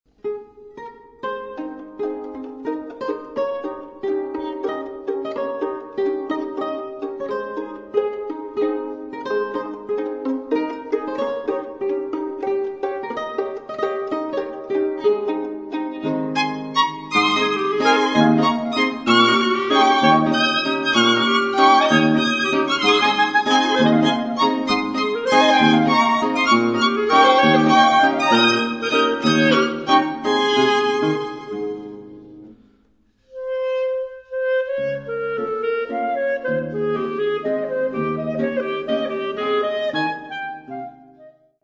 * Quartett mit G-Klarinette
Steinerner Saal, Musikverein Wien